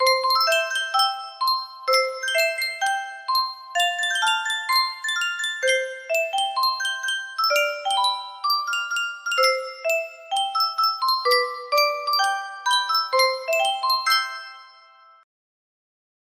Yunsheng Music Box - Mozart Sonata for Two Pianos 5792 music box melody
Full range 60